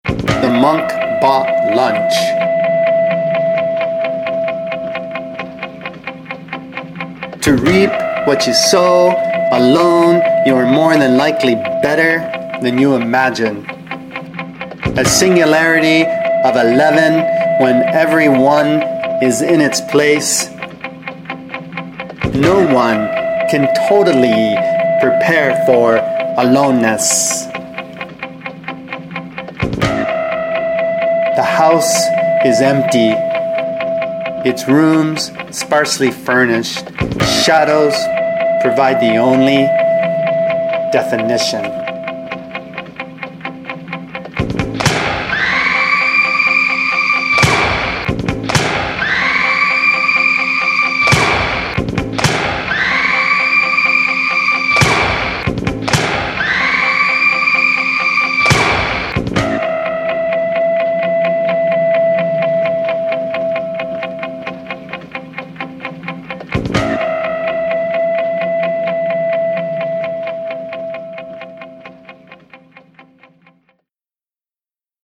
collaborated with me in a one-night session